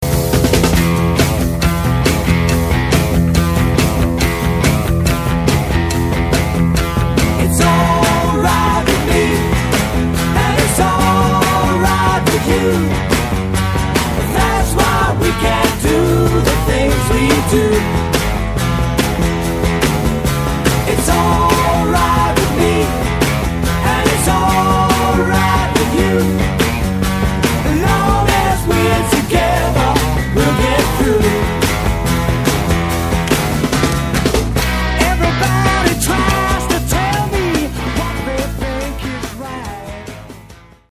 ドラム
ギター
ベース